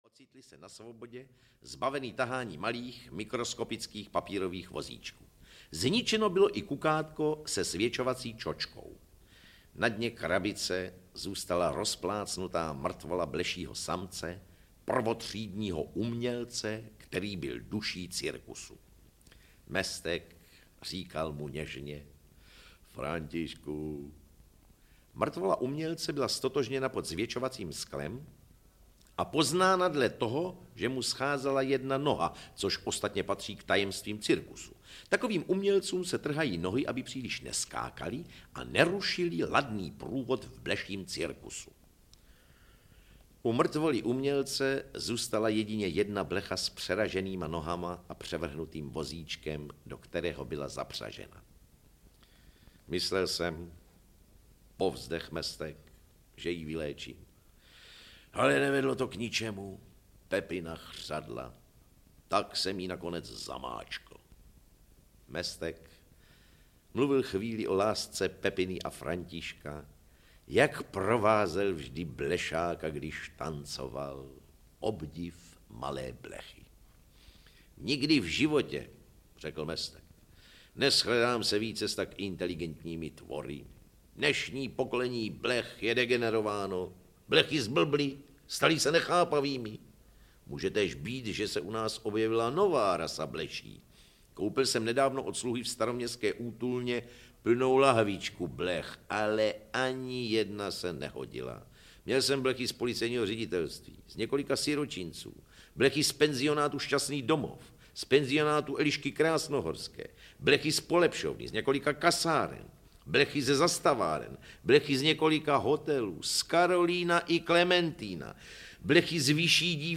Supraphon vypravuje... (Hašek, Čapek, Bass, Pavel, Drda, Hrabal) audiokniha
Ukázka z knihy